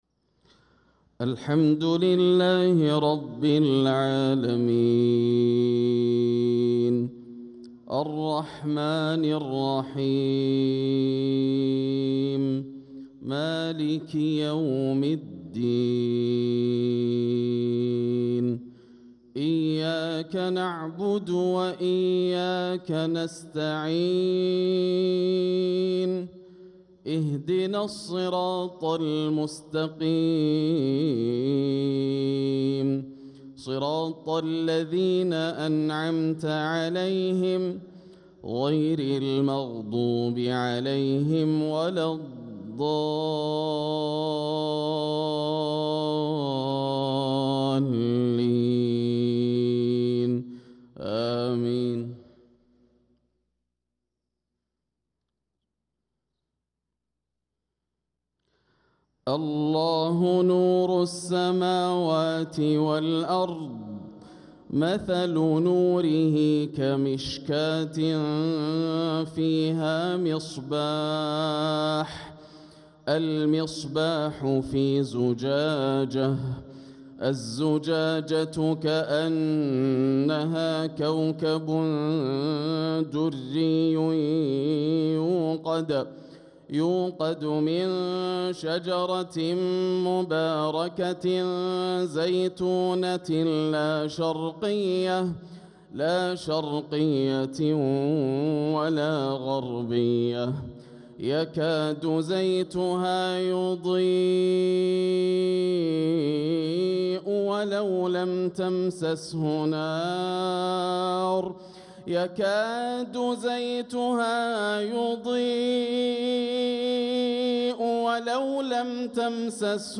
صلاة المغرب للقارئ ياسر الدوسري 20 ربيع الآخر 1446 هـ